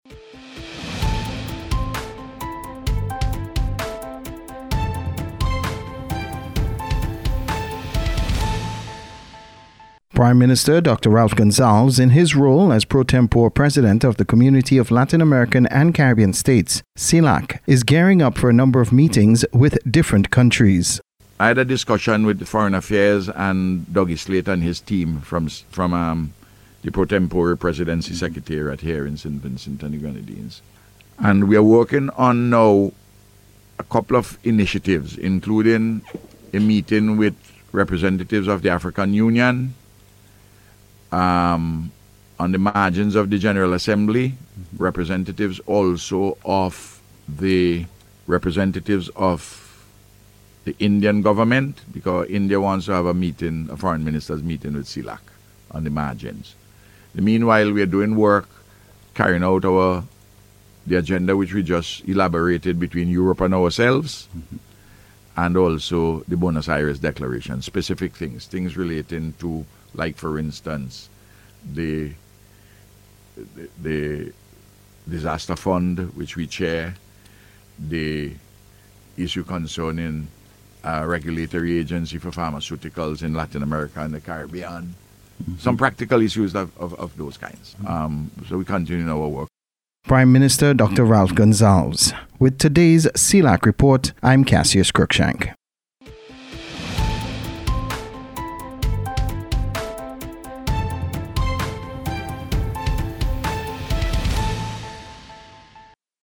CELAC-VOX.mp3